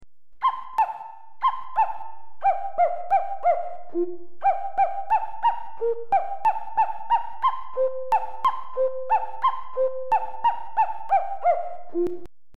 動物に関する歌をその動物の声で歌わせています。
カッコウの鳴き声で歌わせています。
kakkou.mp3